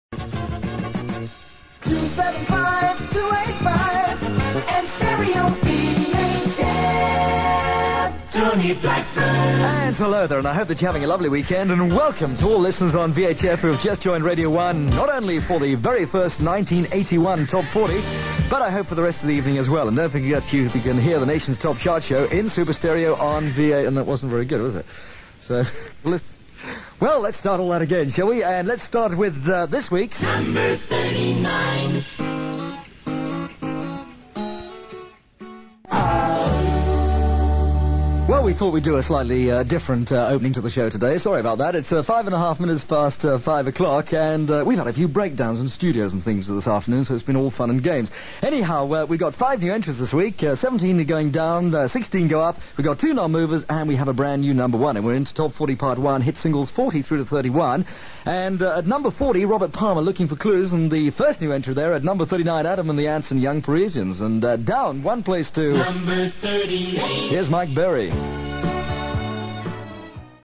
Aired something that was not destined for so being. In the fortunate examples - such as this - there are no expletives. Tony likely kicked himself, though, as a perfect chart opener was not aired on 11th Jan 1981 as he no doubt produced - but an earlier version.